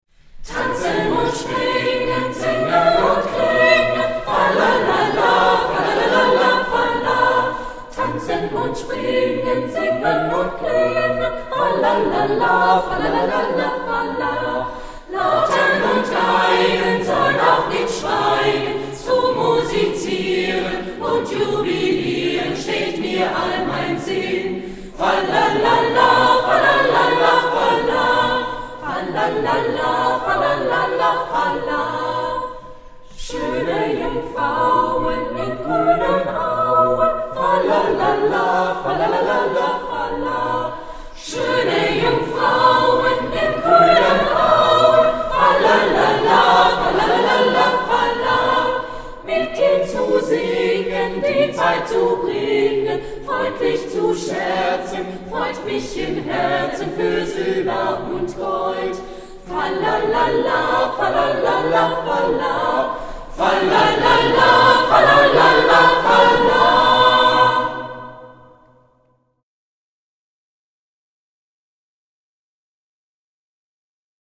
Chor
Hier kannst du dir zwei Lieder des Jugendchores anhören: (MP3-Format) Kein schöner Land in dieser Zeit Tanzen und Springen Was gibt es noch alles so in Musik bei uns zu entdecken: Ein Musikzimmer verfügt über eine richtige Orgel.